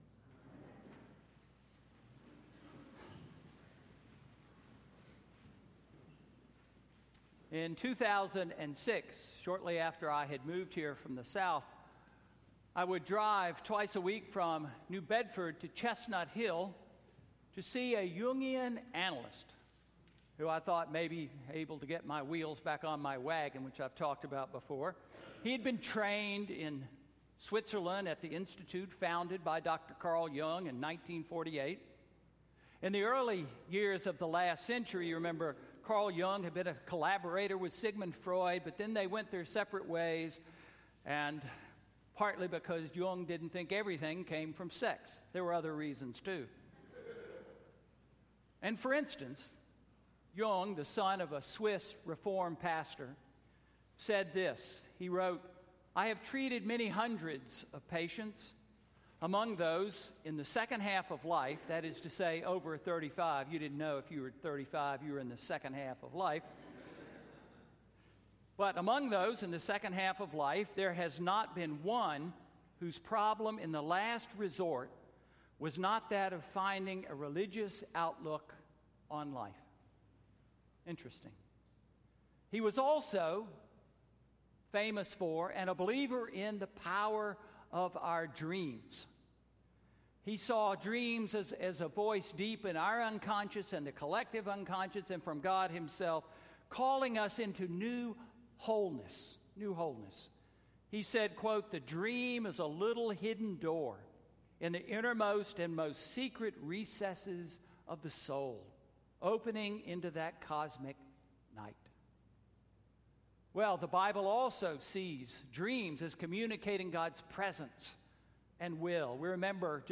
Sermon–Advent 4–December 18, 2016